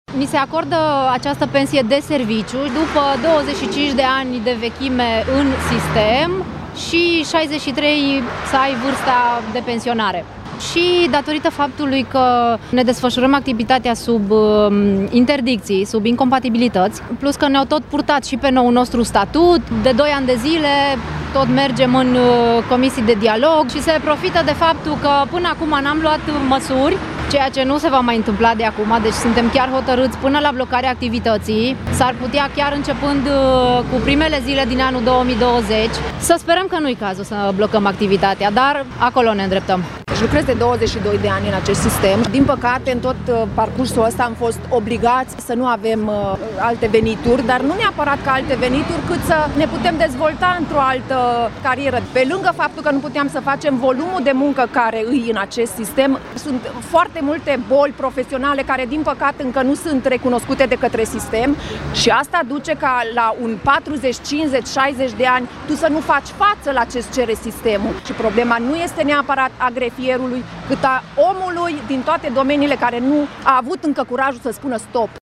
18-dec-mures-protest-voxuri-grefieri.mp3